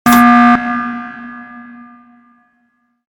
emergency.mp3